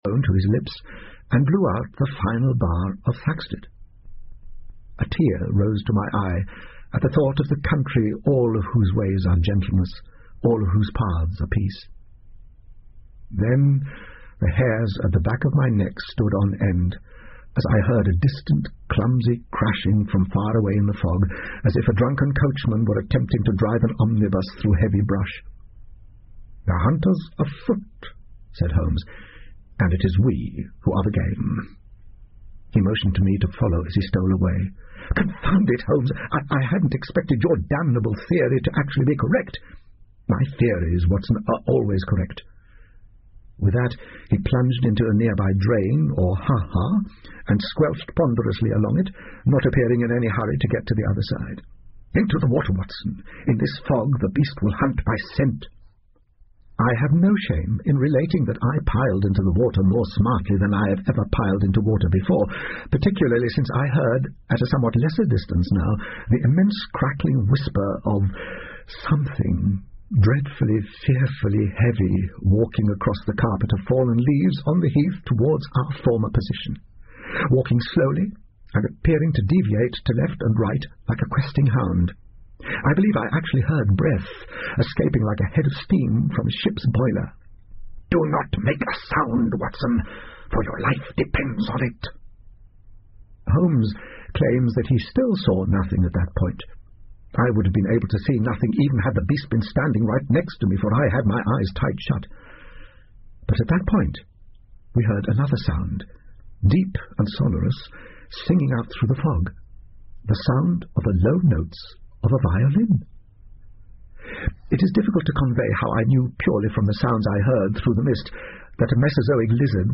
福尔摩斯广播剧 Cult-The Lost World 5 听力文件下载—在线英语听力室
在线英语听力室福尔摩斯广播剧 Cult-The Lost World 5的听力文件下载,英语有声读物,英文广播剧-在线英语听力室